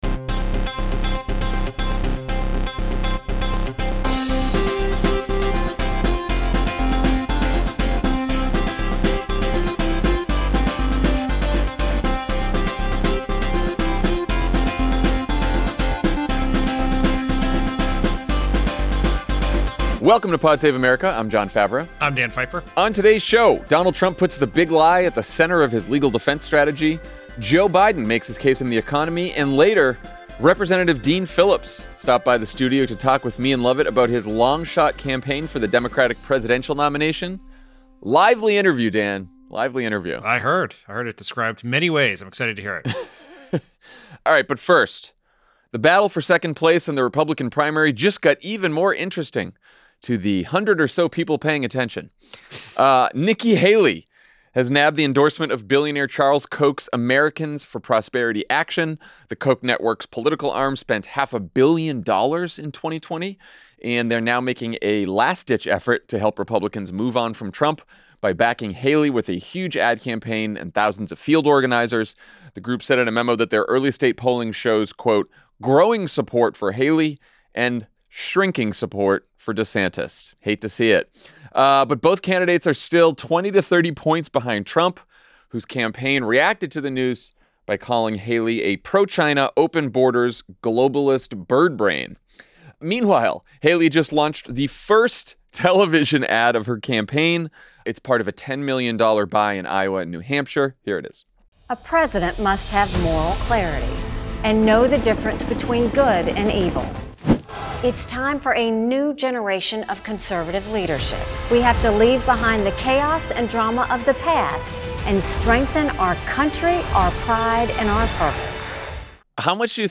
Trump's lawyers preview a new defense strategy, Nikki Haley lands a big new endorsement, and President Biden fights back on the economy—and hits Lauren Boebert in her own district. Then, Minnesota Rep. Dean Phillips visits the studio for a heated conversation about why he's running against Biden in the Democratic primary, what Democrats should be doing differently, what it would take for him to get out of the race, and of course, the difference between ice cream and gelato. NOTE: the interview with Congressman Phillips has been edited for length and clarity.